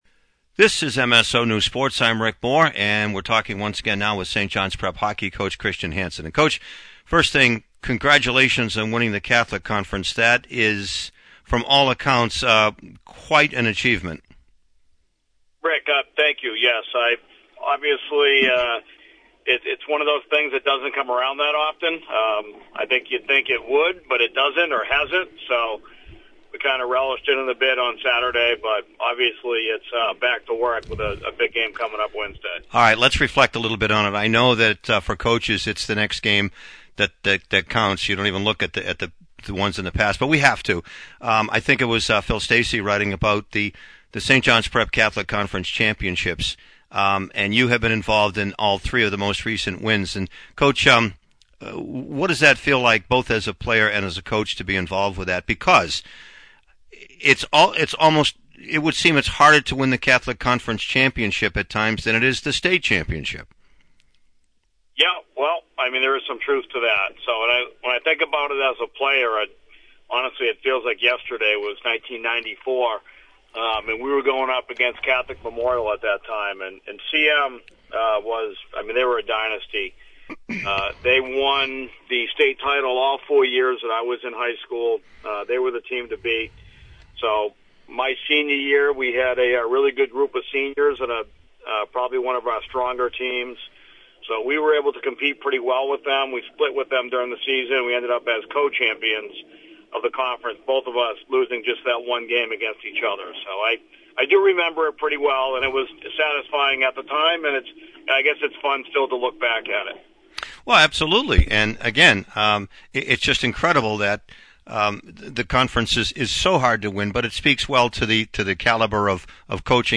Post-game, Pre-game